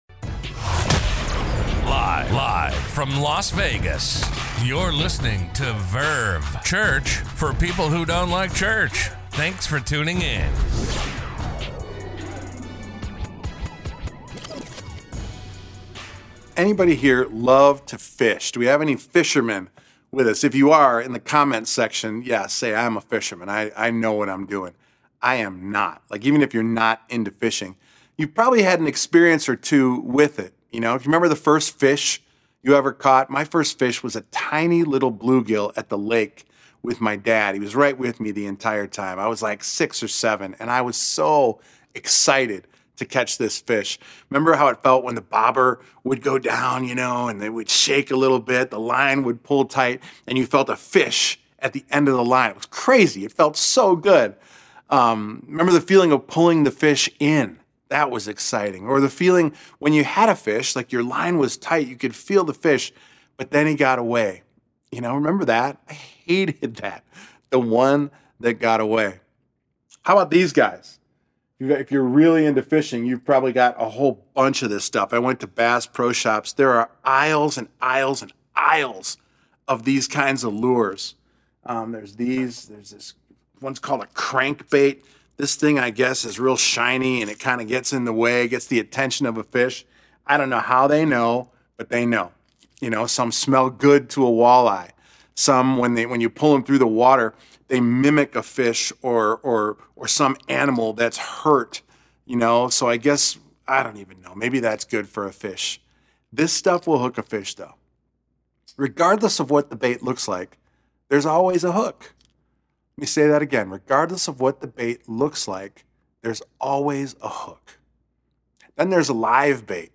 A message from the series "Hot Ones!." Do you want to go deeper in your relationship with God?